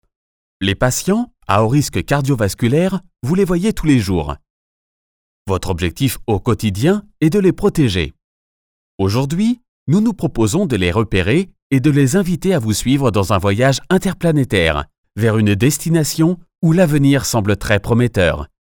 He owns his home studio with ISDN and Neumann microphone His voice and studio are accredited by SaVoa (Society of Accredited Voice Over Artists). His style can be : convincing, reassuring, dynamic, soft, sensitive, elegant, Warm.
Sprechprobe: eLearning (Muttersprache):